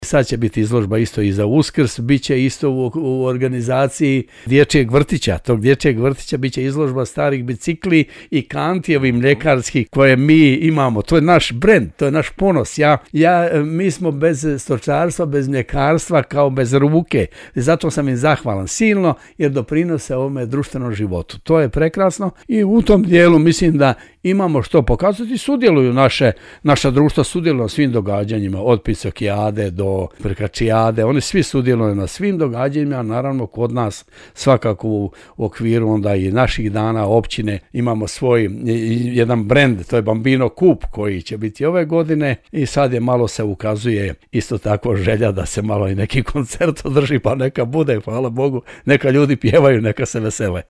-rekao je načelnik Milinković u emisiji Susjedne općine.